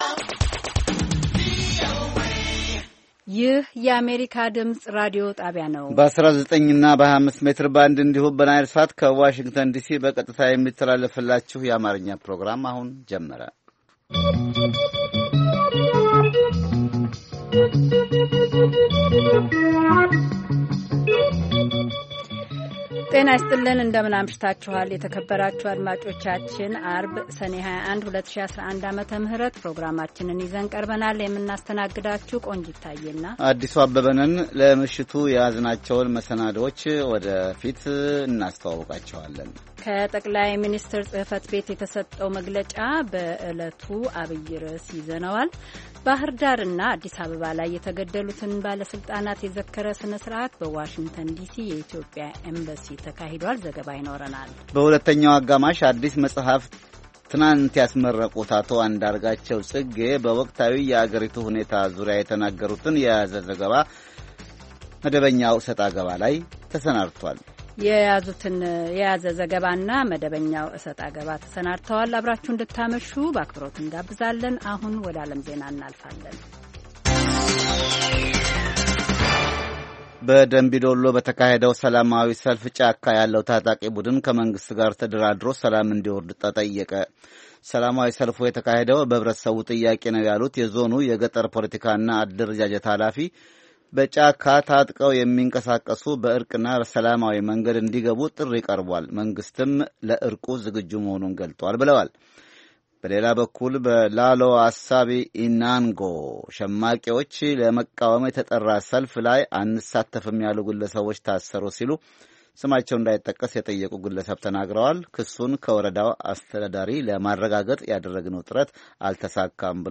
ዐርብ፡-ከምሽቱ ሦስት ሰዓት የአማርኛ ዜና